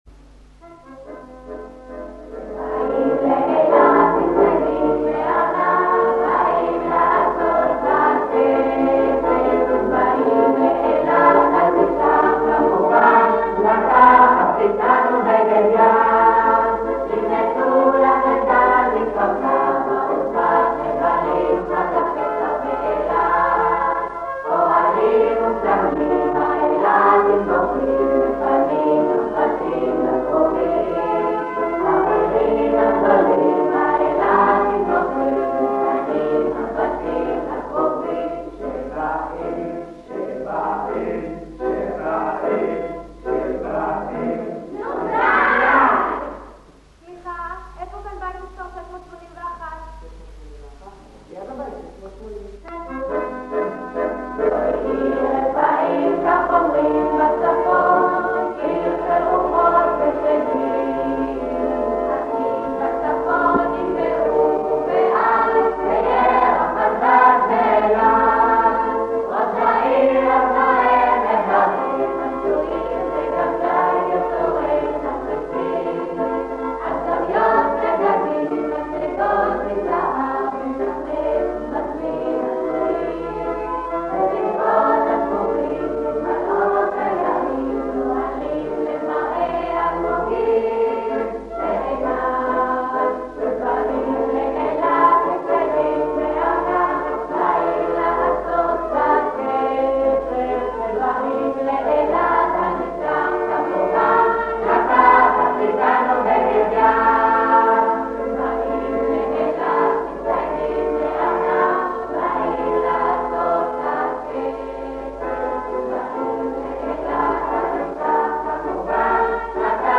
ההקלטות, על סלילים שנשמרו 40 שנה, נשמעות קצת עתיקות, אבל אי אפשר לפספס את הקולות, האקורדיאון והראשוניות!